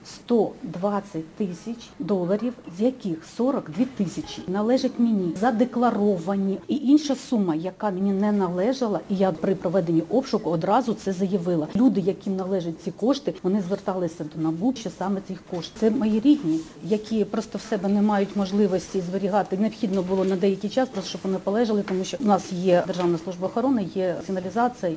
Лідія Гаврилова сьогодні у коментарі журналістам сказала, що золоті злитки загальною вагою 75 грамів – подаровані їй близькими родичами, і що вона збиралася їх внести в електронну декларацію за підсумками 2017-го року. А більша частина коштів, знайдених у неї під час обшуку детективами НАБУ, належить членам її родини, які віддали їй гроші на зберігання, каже Лідія Гаврилова.